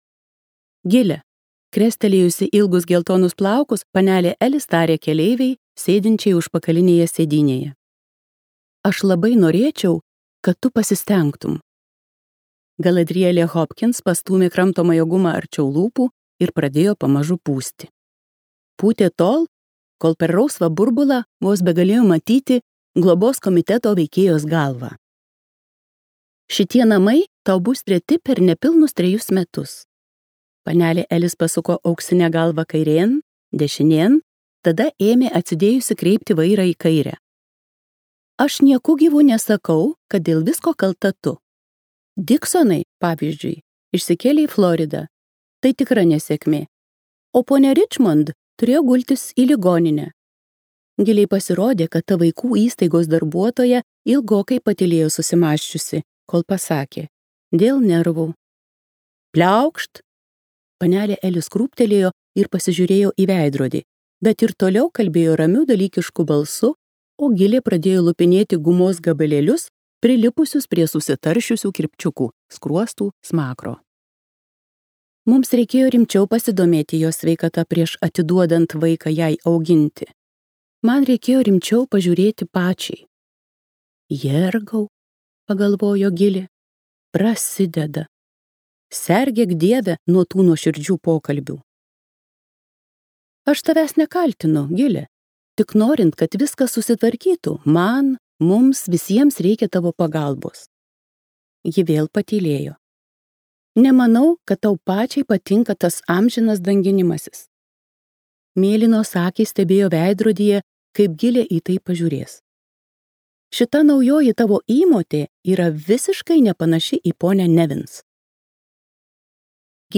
Smarkuolė Gilė Hopkins | Audioknygos | baltos lankos